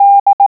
Click on a letter, number, or punctuation mark to hear it in Morse code.